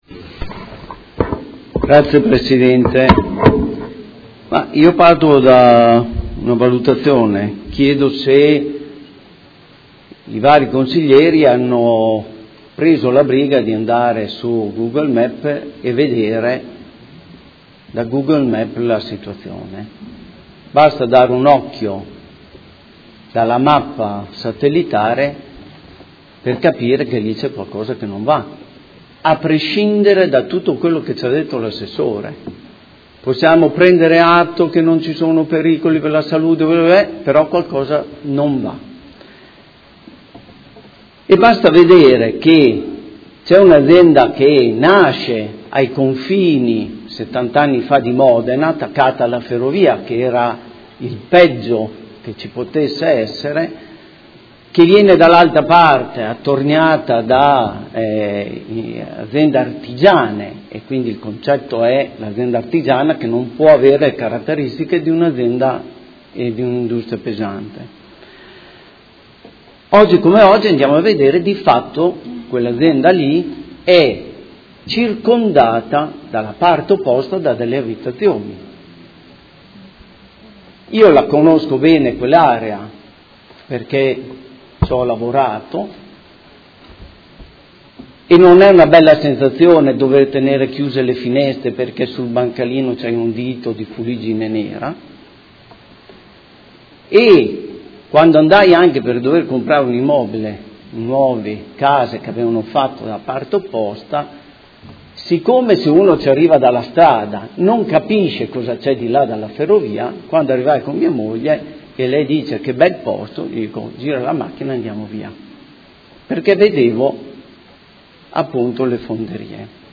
Antonio Montanini — Sito Audio Consiglio Comunale
Seduta del 18/05/2017. Dibattito su Ordine del Giorno, Mozioni ed emendamento riguardanti le Fonderie Cooperative Riunite